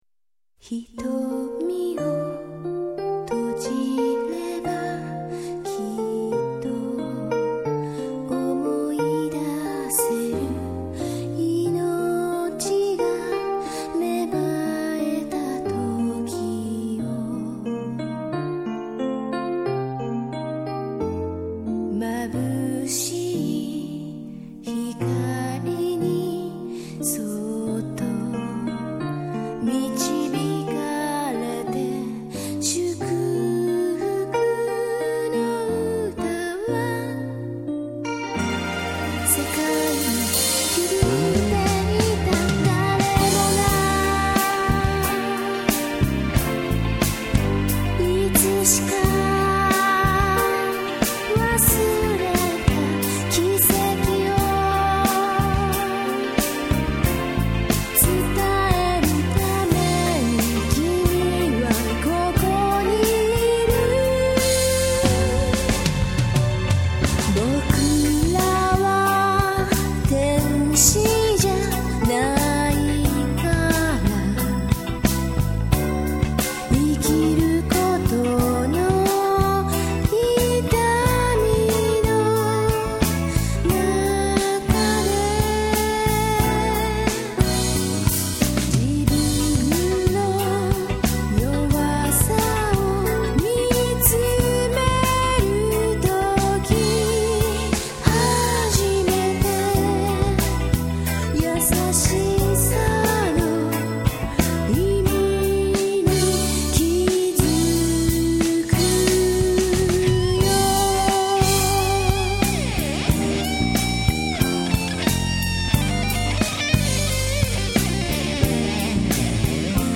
songs